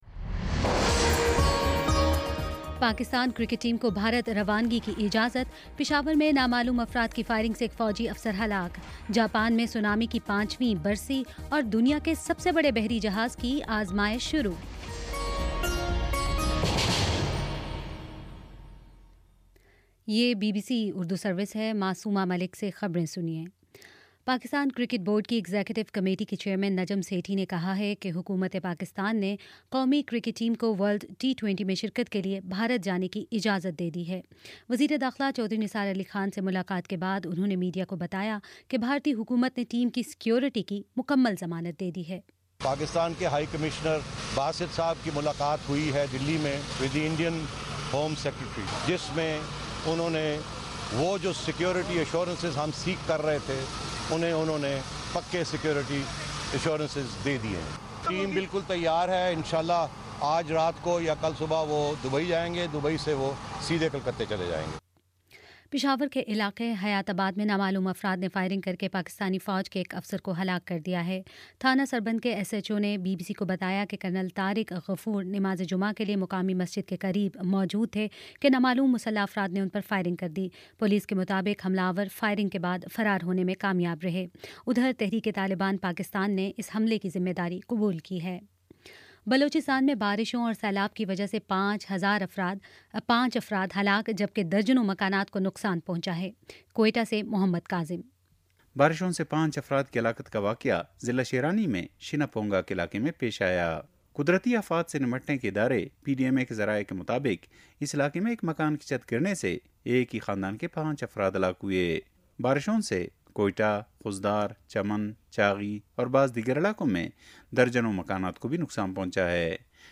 مارچ 11 : شام چھ بجے کا نیوز بُلیٹن